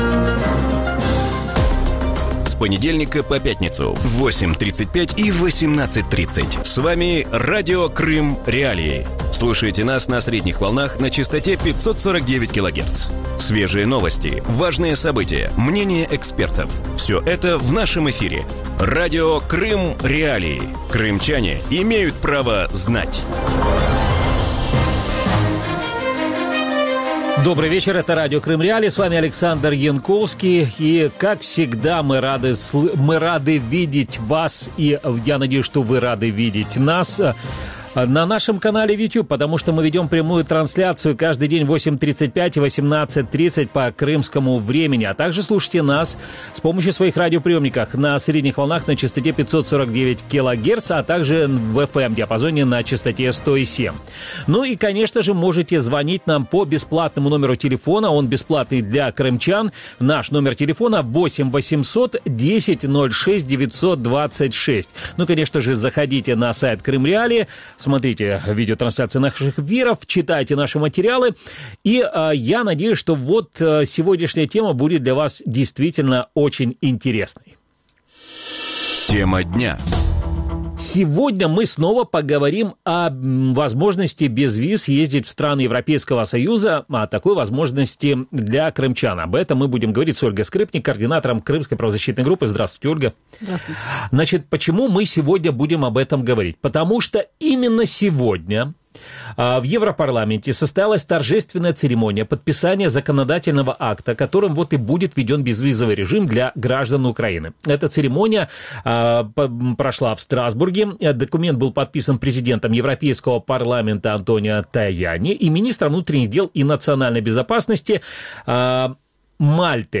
У вечірньому ефірі Радіо Крим.Реалії обговорюють умови отримання біометричних паспортів для кримчан і виїзду до країн Євросоюзу. Як кримчанам отримати біометричний паспорт в Україні?